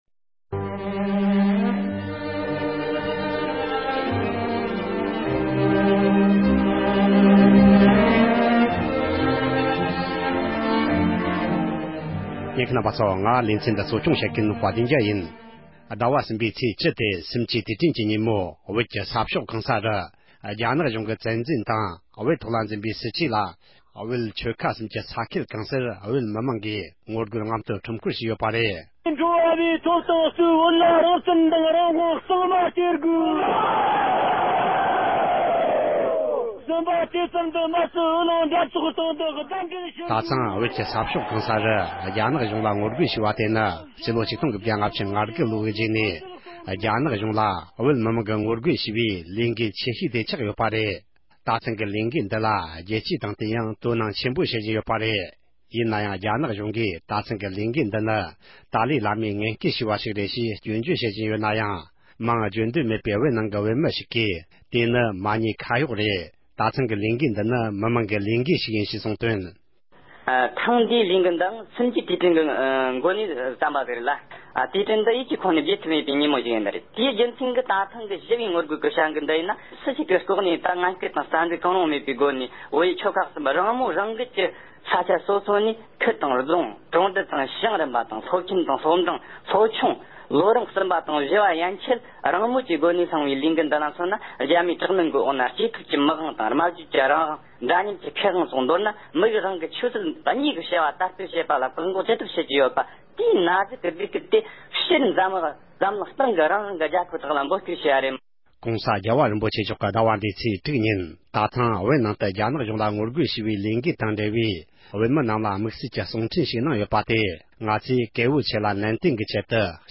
ཨ་མདོའི་སྐད་ནང་རྒྱང་སྲིང་བྱས་པར་ཉན་རོགས་ཞུ༎